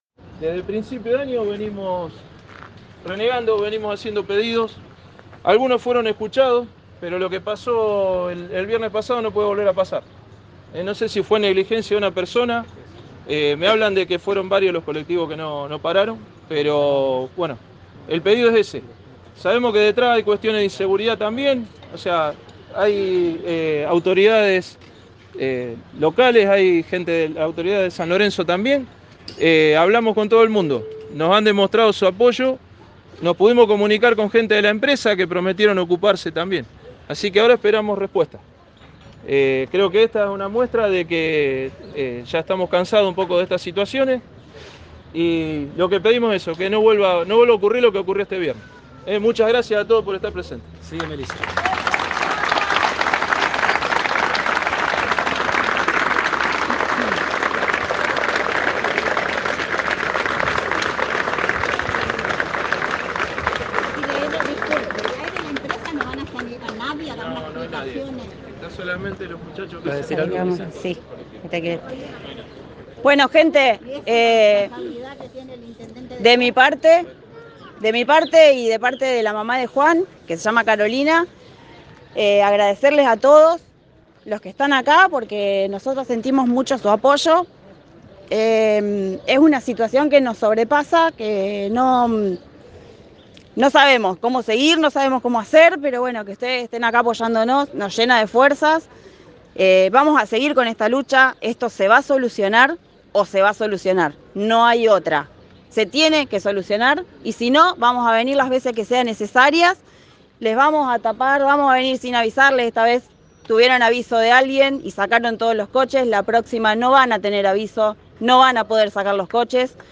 Cierran esta manifestación de la comunidad educativa y expresan su agradecimiento a todos los que acompañaros esta marcha.